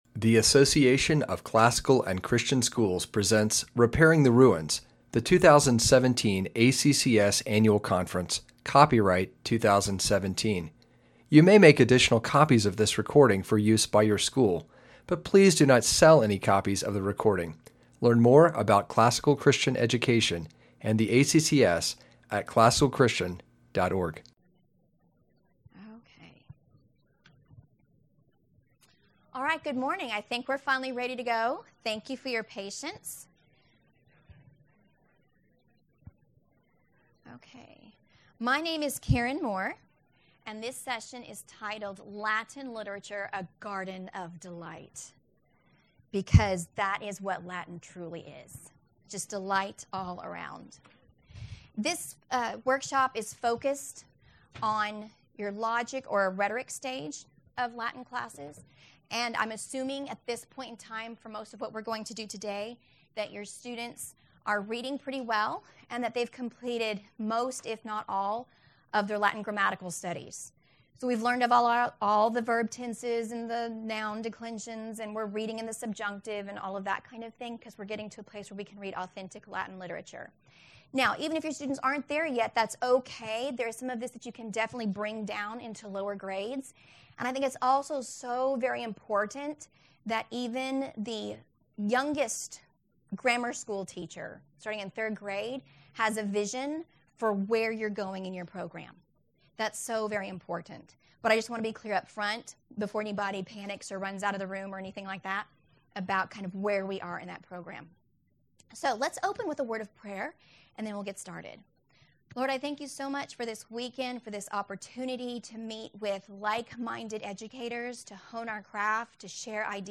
2017 Foundations Talk | 0:56:53 | All Grade Levels, Latin, Greek & Language
Jan 9, 2019 | All Grade Levels, Conference Talks, Foundations Talk, Latin, Greek & Language, Library, Media_Audio | 0 comments